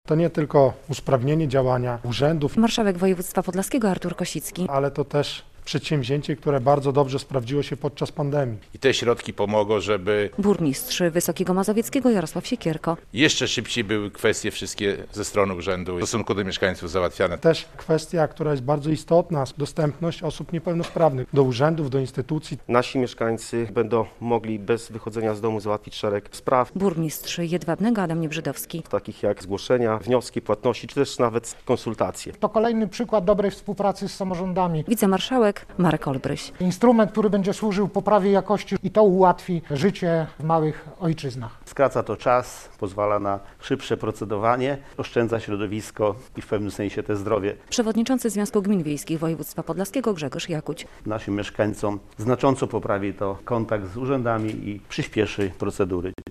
Dotacje na rozwój e-usług w podlaskich samorządach - relacja